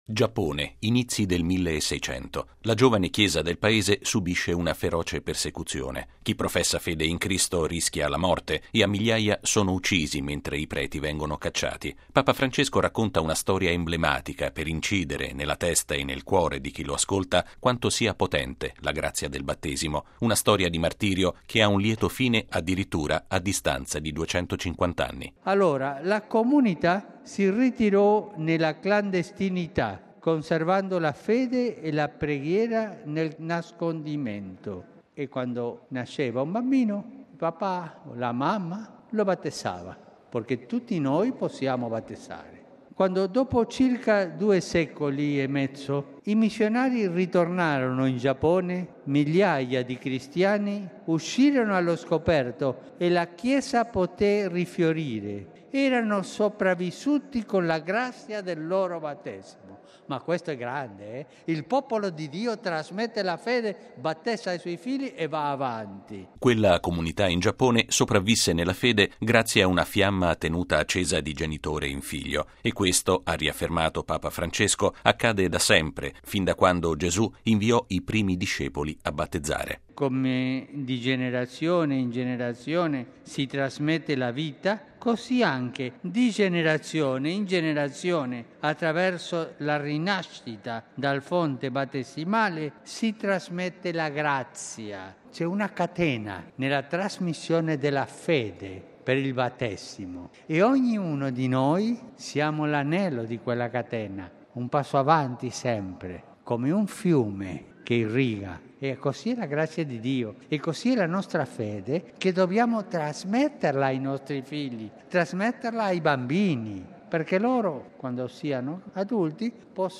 Udienza generale. Il Papa: il Battesimo ci rende tutti anelli di una catena di grazia
Lo ha affermato questa mattina Papa Francesco, di fronte a oltre 30 mila persone in Piazza San Pietro, nella seconda udienza generale dedicata a questo Sacramento. In particolare, il Papa ha offerto sostegno ai cristiani di Terra Santa e Giordania invitandoli a essere forti nelle persecuzioni.